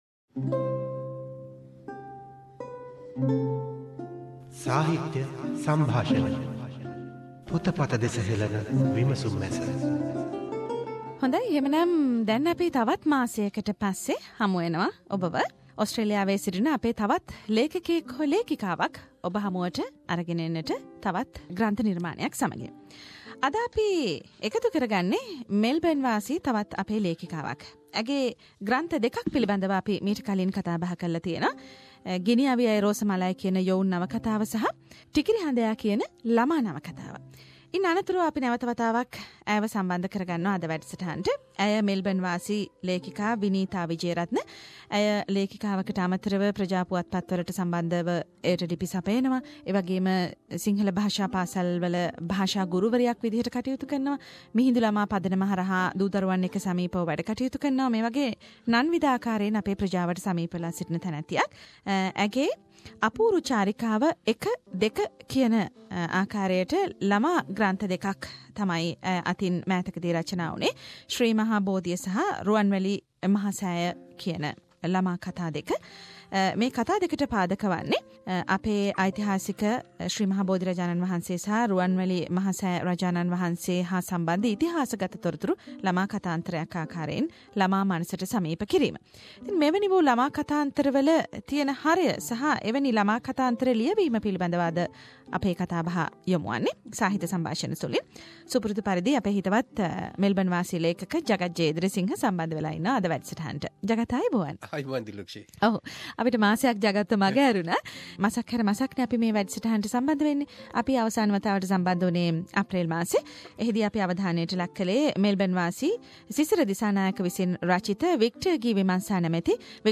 SBS Sinhalese monthly literary discussion reviewed the Apuru Charika 1&2 - The book consists the information of buddhist historical places in Sri Lanka.